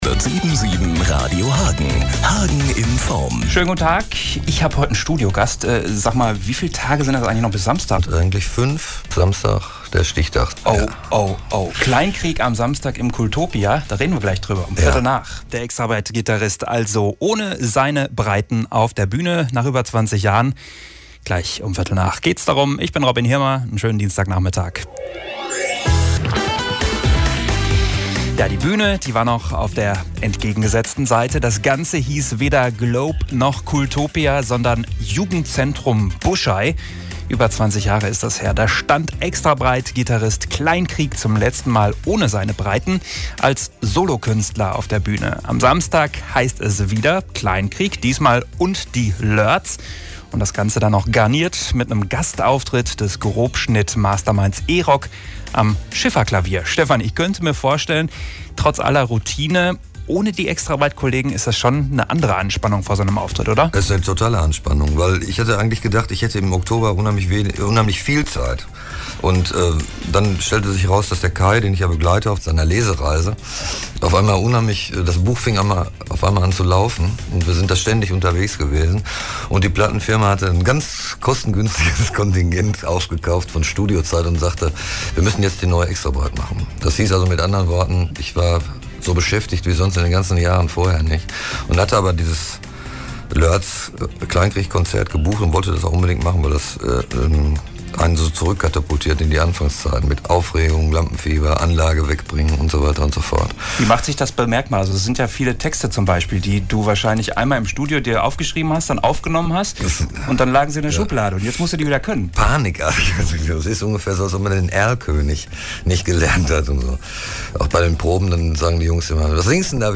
Als Schmankerl gibt es am Ende des Interviews die ersten Takte des “HAGEN” songs zu hören. Leider nur ohne den Mitgröhl-Part.